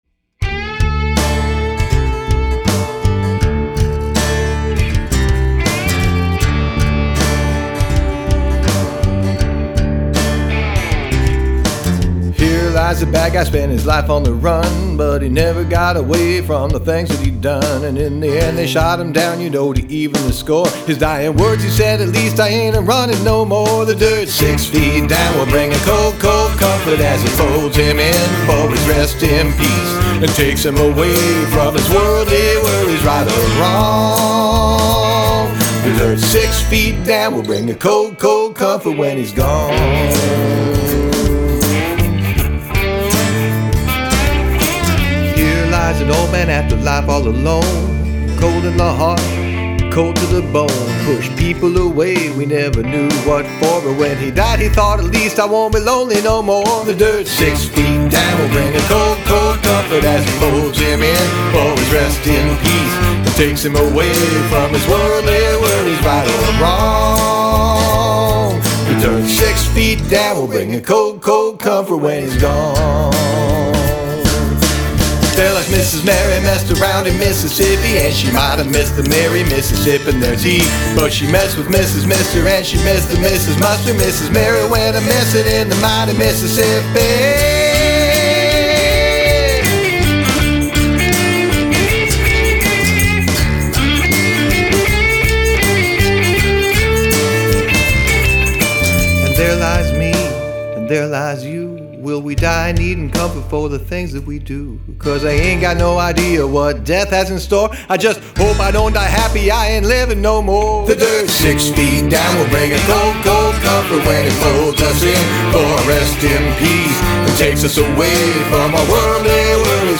Tongue Twister
+++ outlaw country swagger +++
+++ god, I love that distorted slide guit +++